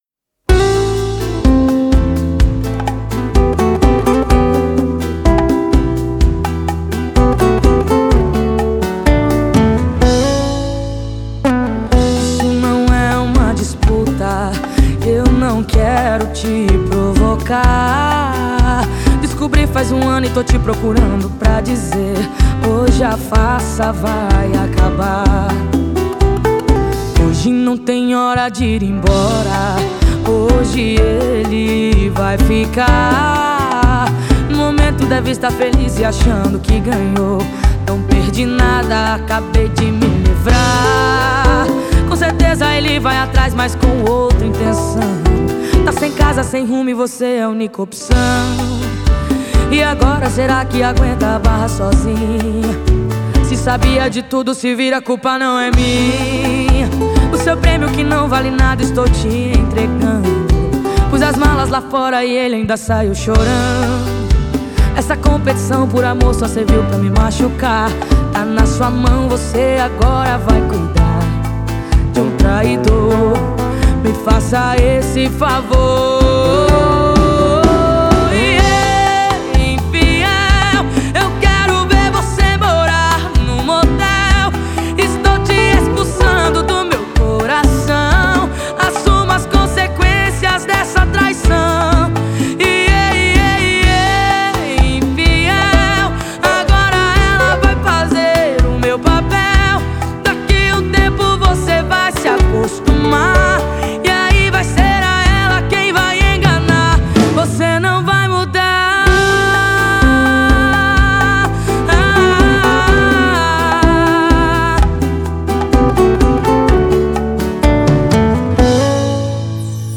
2025-01-02 18:45:45 Gênero: Sertanejo Views